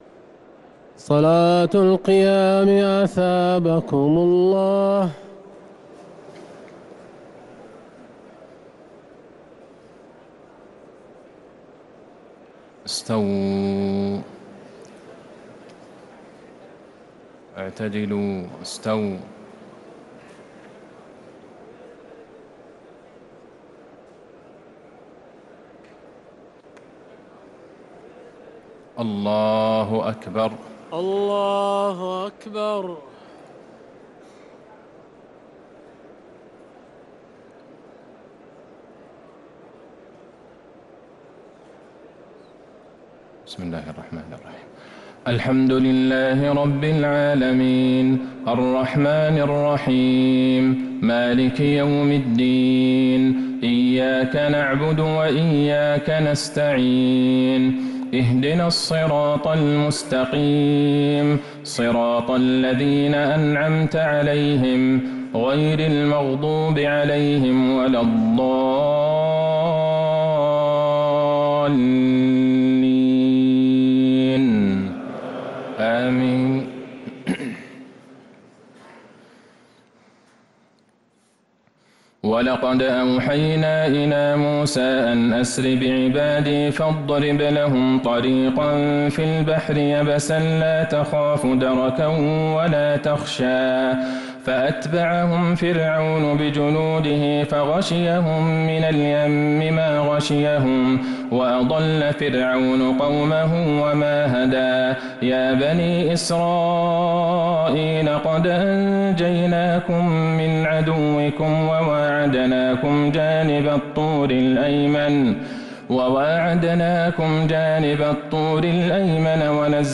تهجد ليلة 21 رمضان 1447هـ من سورتي طه (77-135) و الأنبياء (1-41) | Tahajjud 21st night Ramadan 1447H Surah Taha and Al-Anbiya > تراويح الحرم النبوي عام 1447 🕌 > التراويح - تلاوات الحرمين